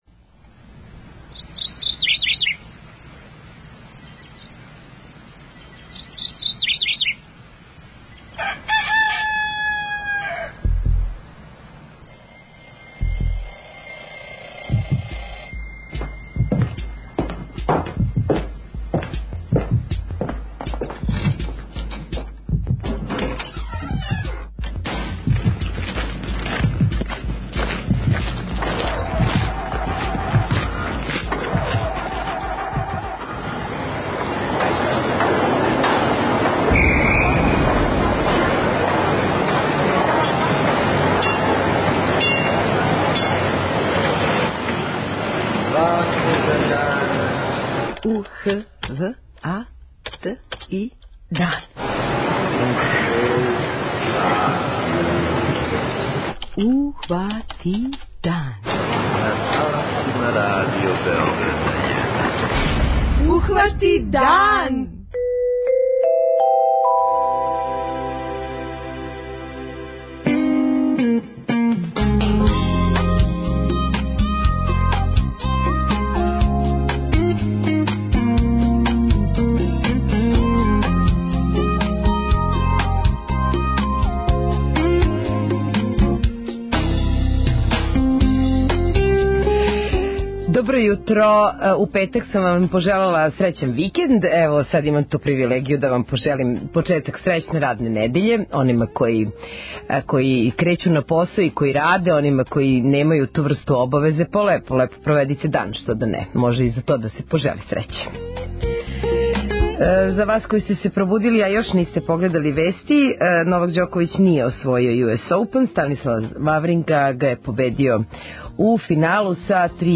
преузми : 21.57 MB Ухвати дан Autor: Група аутора Јутарњи програм Радио Београда 1!